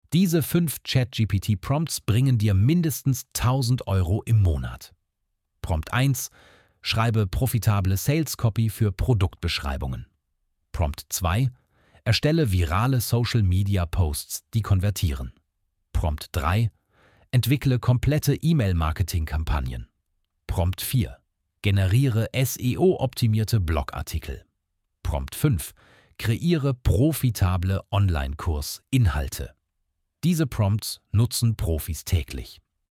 Automatisch generierte Videos mit deutscher KI-Stimme
• Stimme: Stefan (ElevenLabs German)
🎤ElevenLabs TTS: Stefan Voice (Deutsche Native Speaker)